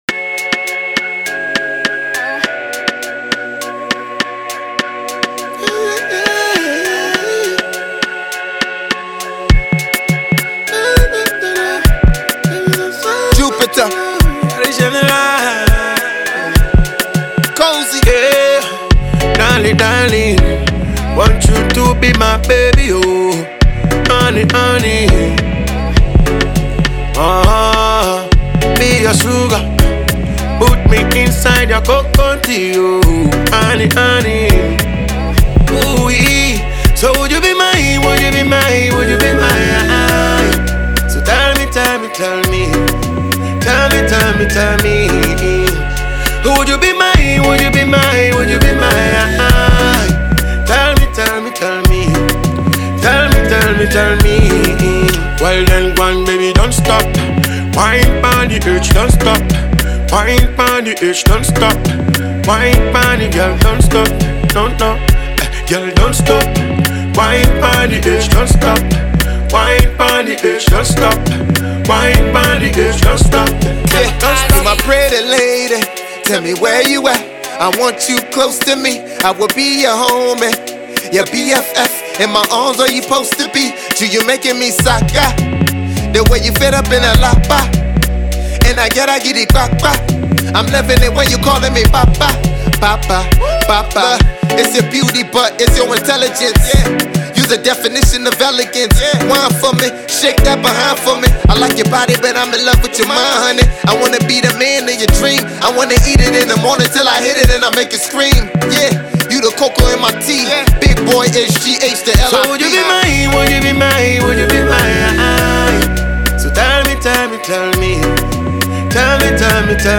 Ghana and Liberia linkup fire vibe.